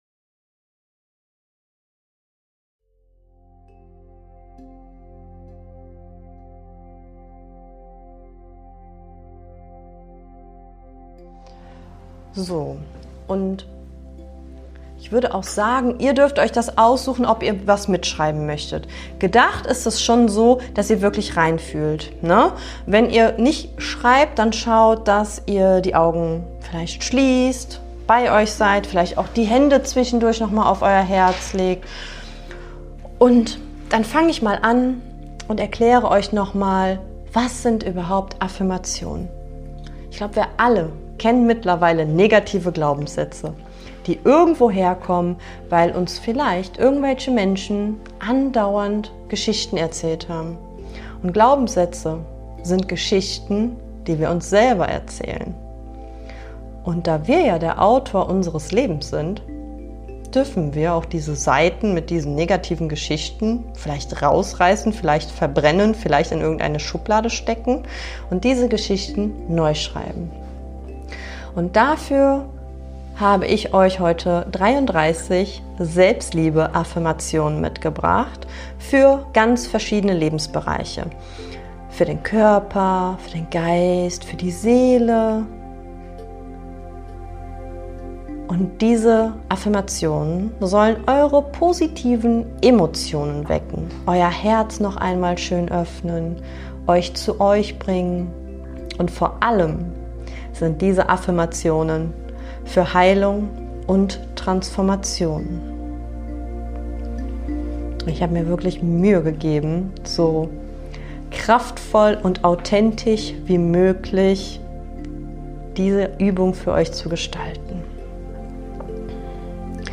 Während des "Selflove Festival" Coaching Workshops habe ich diese Meditation zu 33 Affirmationen mitgefilmt.